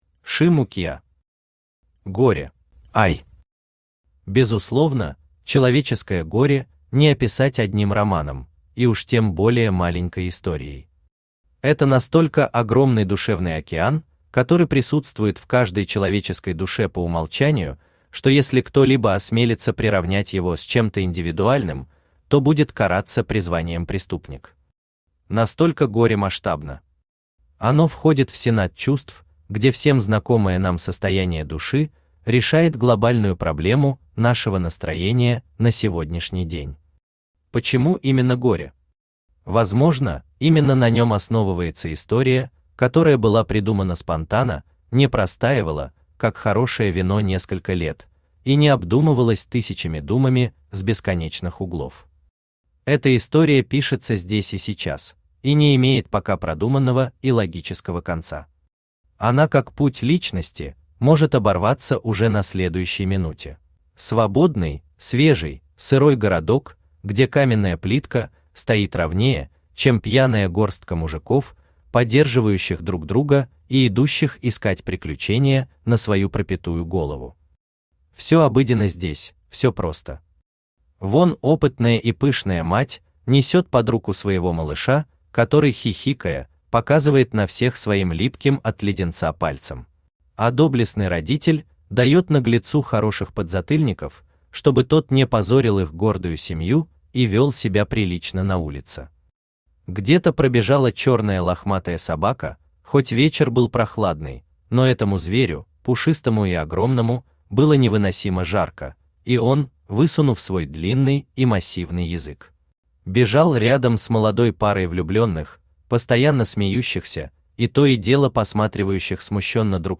Aудиокнига Горе Автор Шиму Киа Читает аудиокнигу Авточтец ЛитРес.